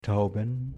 Ääntäminen
Ääntäminen Tuntematon aksentti: IPA: /ˈtaʊ̯bən/ IPA: [ˈtʰaʊ̯bən] IPA: [-bn̩] IPA: [-bm̩] Haettu sana löytyi näillä lähdekielillä: saksa Käännöksiä ei löytynyt valitulle kohdekielelle. Tauben on sanan Taube monikko.